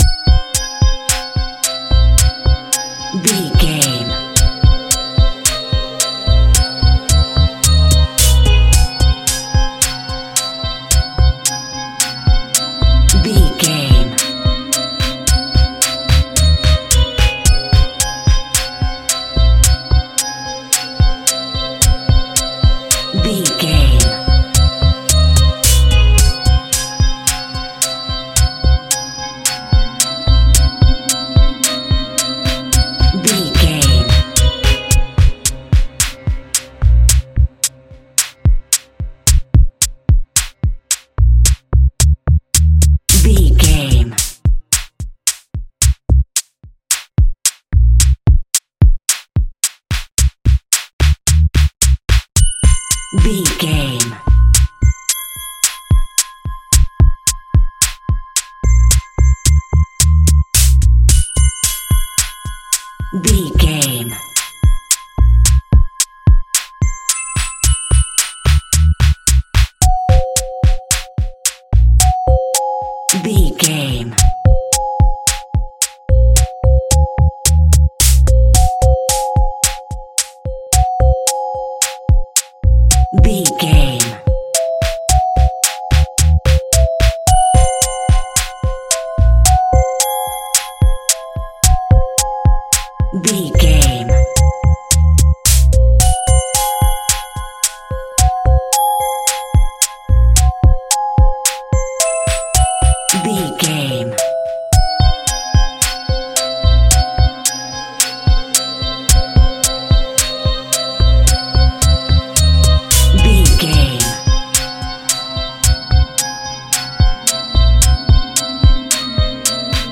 Thriller Music Cue.
Aeolian/Minor
tension
ominous
eerie
groovy
synthesiser
electric piano
drums
percussion
viola
orchestral instruments